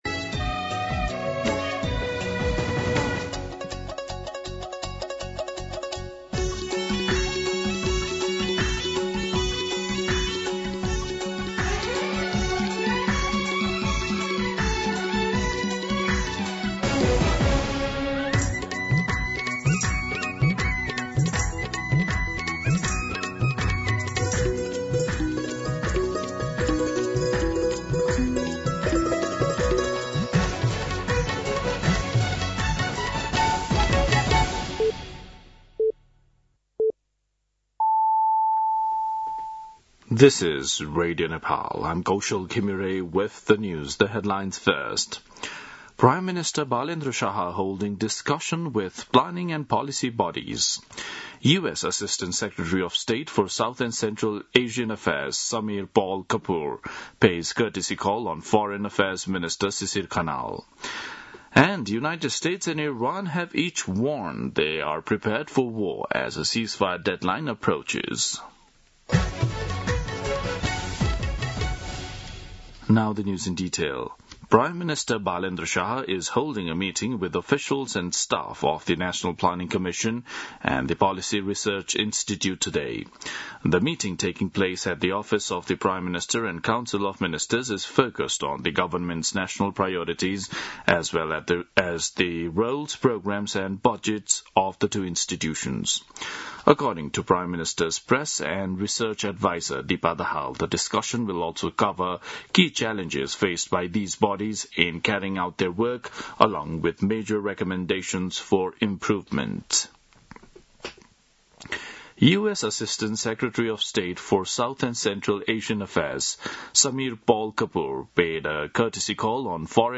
दिउँसो २ बजेको अङ्ग्रेजी समाचार : ८ वैशाख , २०८३
2pm-English-News-08.mp3